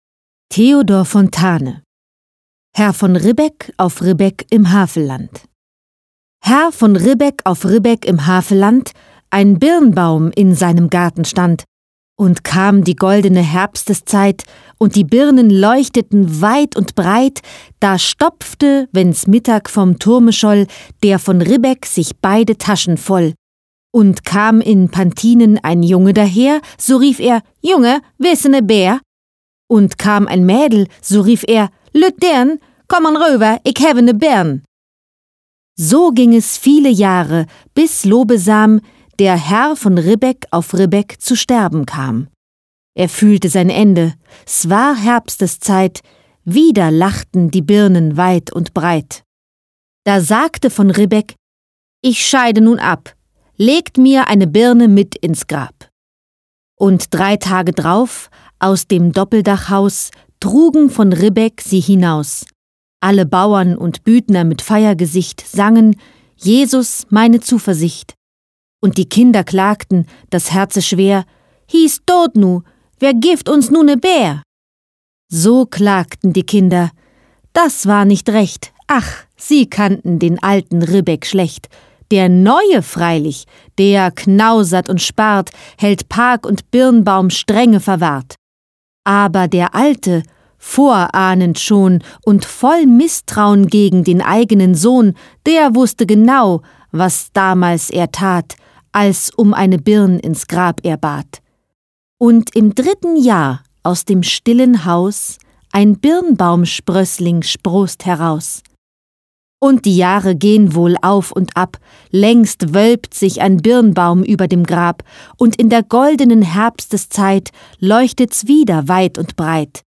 Balladen, Lyrik, Schullektüre